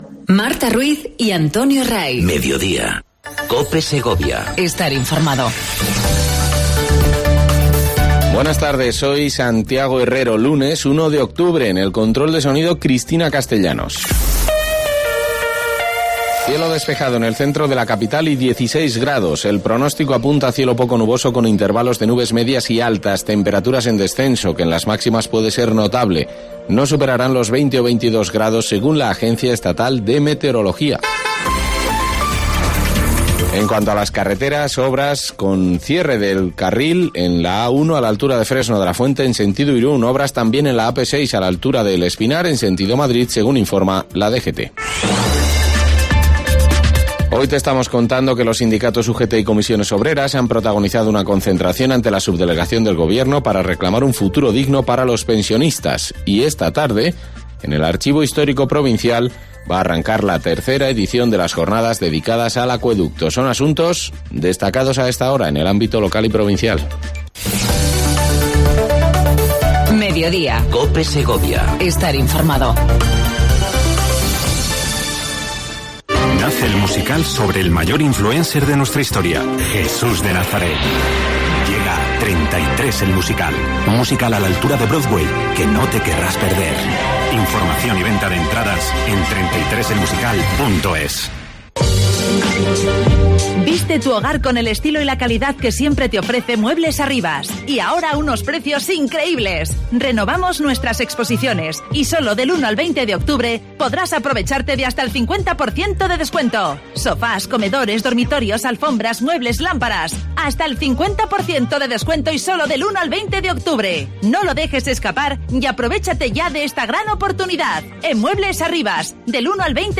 AUDIO: Entrevista a Francisco Vázquez presidente de la diputación provincial